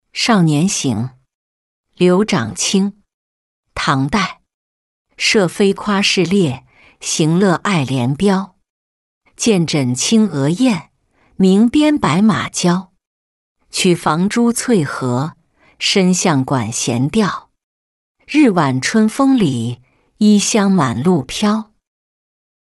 少年行-音频朗读